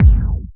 Strong Bottom End Steel Kick Drum Sound G Key 477.wav
Royality free kick drum sample tuned to the G note. Loudest frequency: 107Hz
.WAV .MP3 .OGG 0:00 / 0:01 Type Wav Duration 0:01 Size 97,81 KB Samplerate 44100 Hz Bitdepth 16 Channels Stereo Royality free kick drum sample tuned to the G note.
strong-bottom-end-steel-kick-drum-sound-g-key-477-qKi.mp3